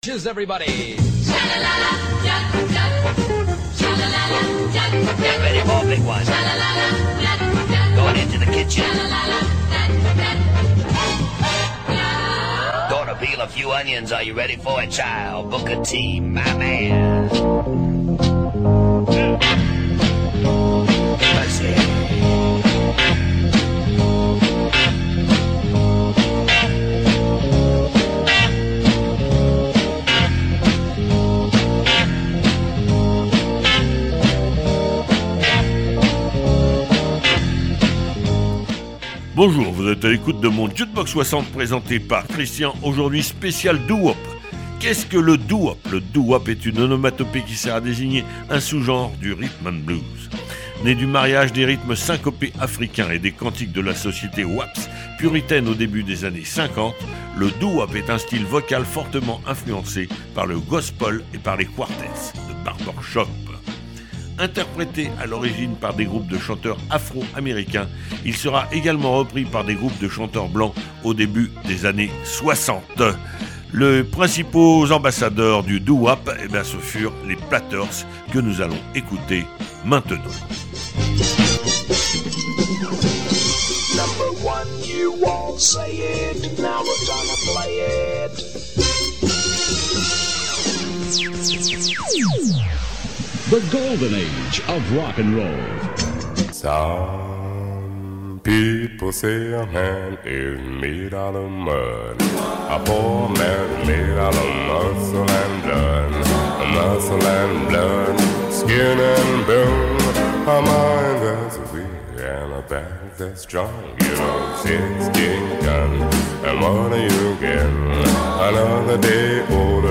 Style musical vocal hérité des negro spirituals, variante du rhythm and blues et du rock and roll populaire dans les années 1950 et 1960, le doo-wop est généralement interprété par de petits ensembles vocaux comportant un soliste ténor qui chante la mélodie tandis que trois ou quatre autres chanteurs produisent des harmonies